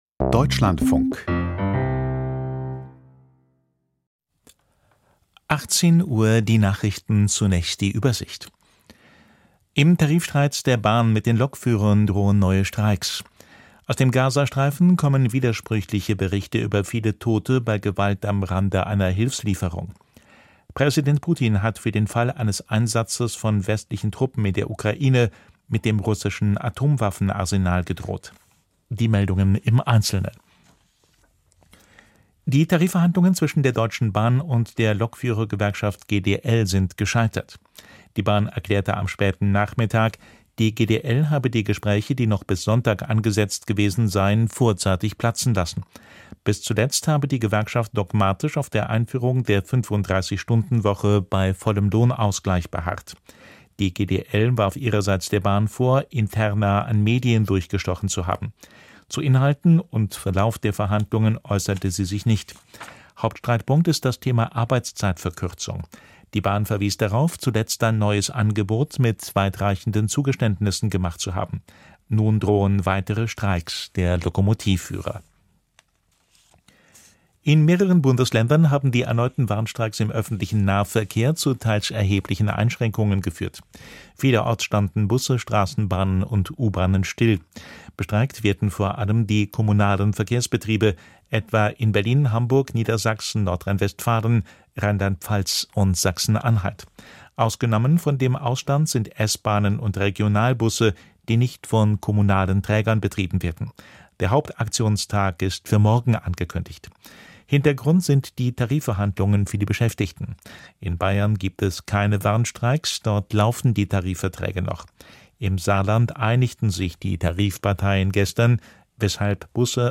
Kommentar zu Israel und Iran: Eskalation abgewendet, Konflikt auf Wiedervorlage - 19.04.2024